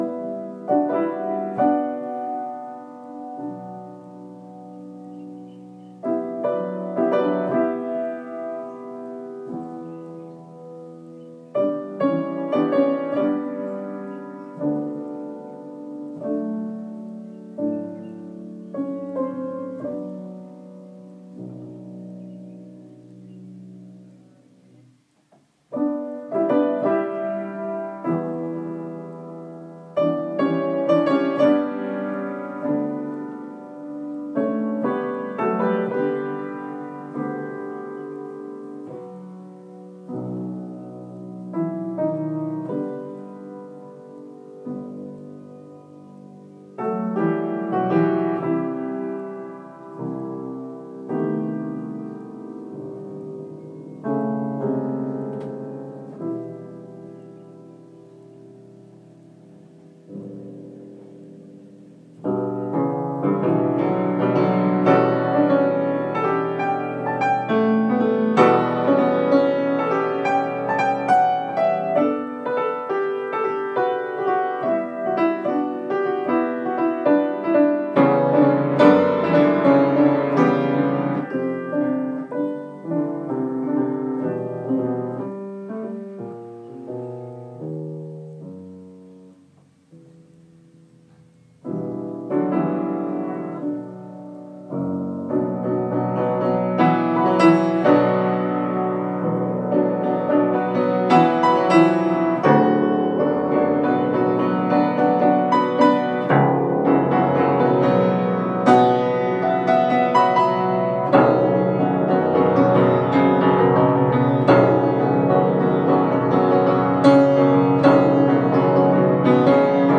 Sergei Rachmaninoff’s prelude evinces a fatalistic, fins de siècle mood. I recorded this piece in Nairobi last year. If one listens carefully one can hear birds in the garden!